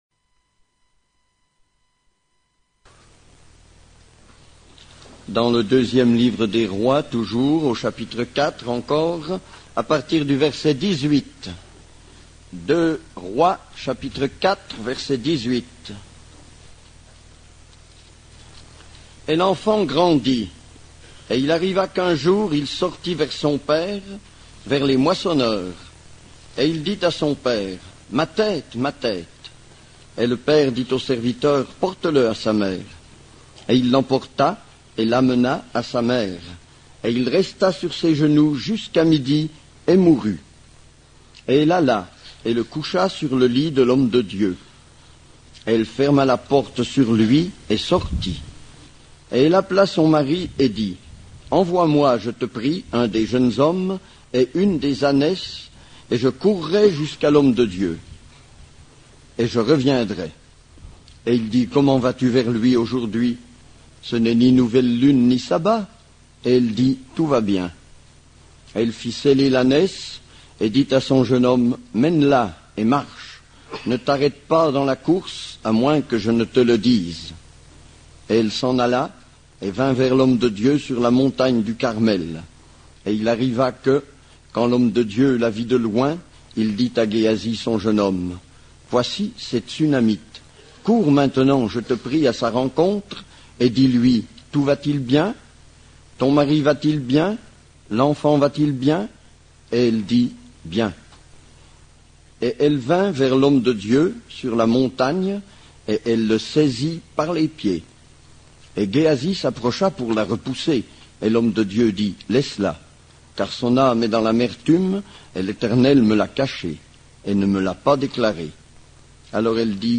Réunions d'étude de La Chapelle Thècle